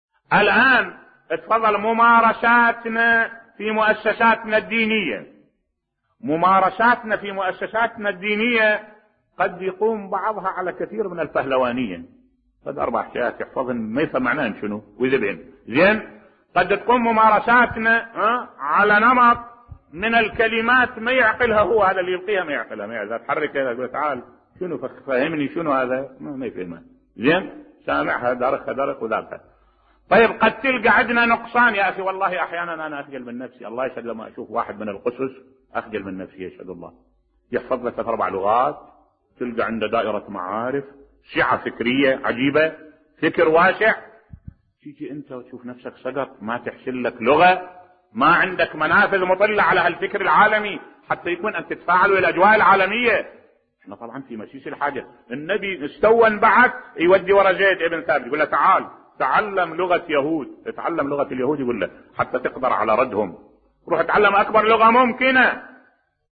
ملف صوتی أخجل عندما أرى أحد القساوسة المسيحيين بصوت الشيخ الدكتور أحمد الوائلي